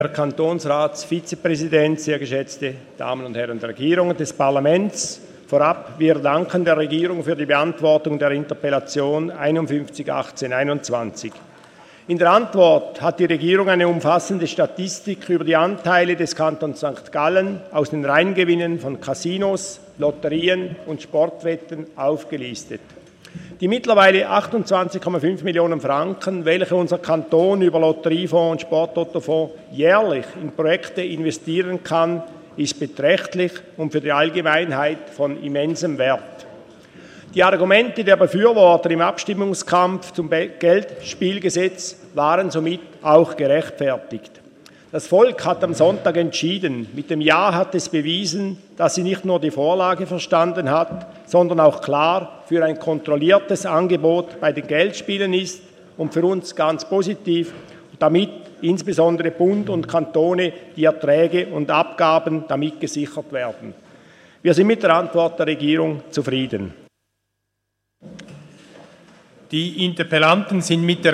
13.6.2018Wortmeldung
Session des Kantonsrates vom 11. bis 13. Juni 2018